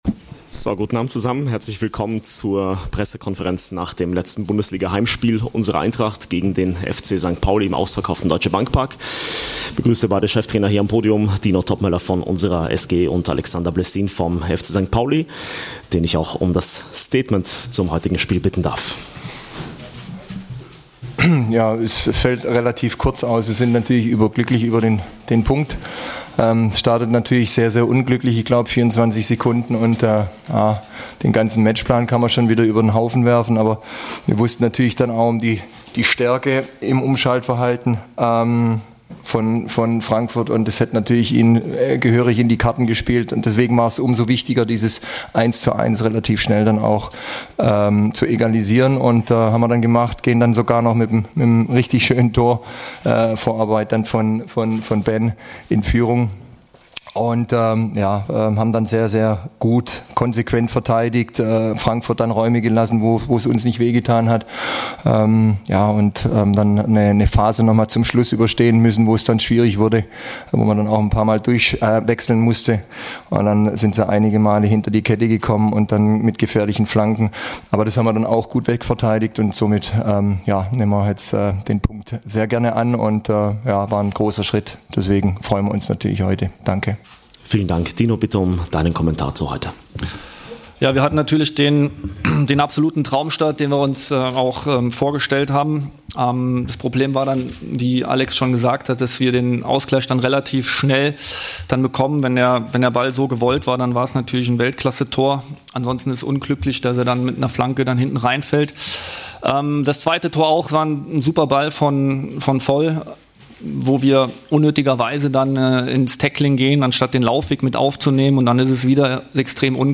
Die Pressekonferenz mit beiden Cheftrainern Dino Toppmöller und Alexander Blessin nach dem Bundesligaspiel gegen den FC St. Pauli.